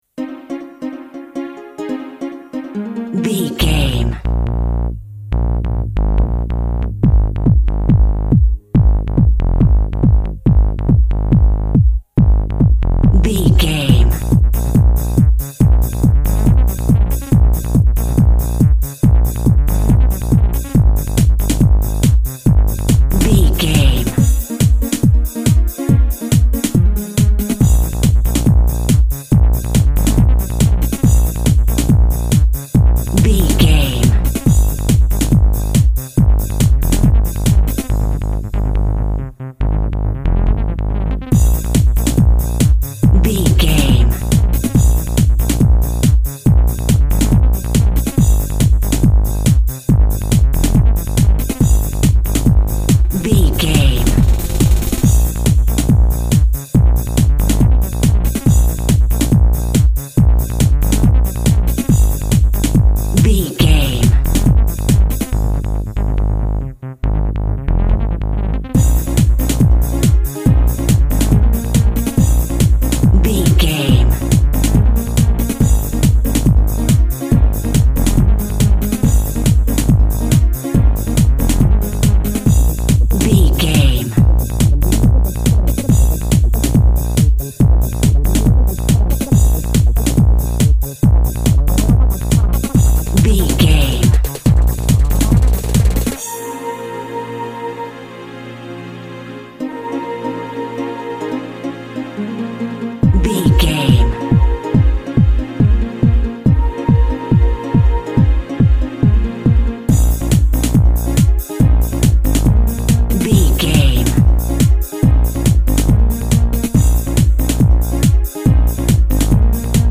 Upbeat House Music.
Ionian/Major
A♭
aggressive
driving
energetic
intense
futuristic
drum machine
synthesiser
electro
dance instrumentals
synth lead
synth bass
electronic drums
Synth Pads